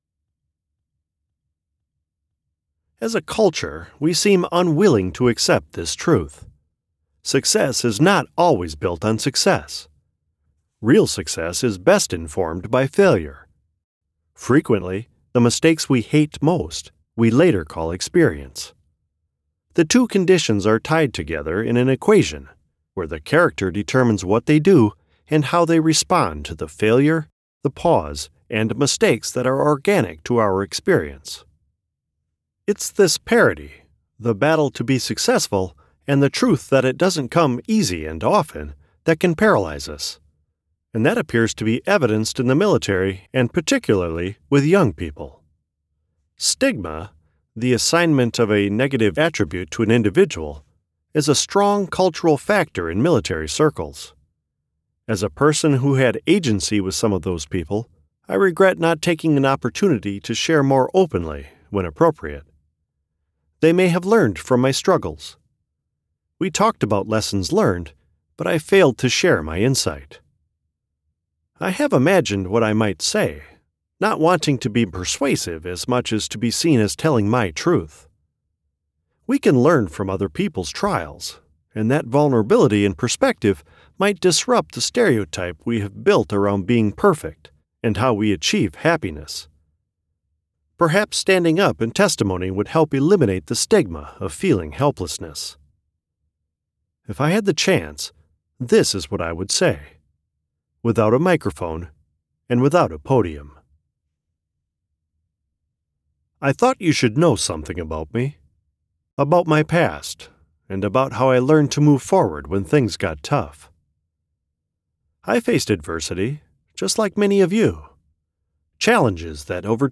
No More Secrets: From Hurt Never Told to Transformational Truth - Retail Audio Sample
North American (General); North American (Midwest)
Middle Aged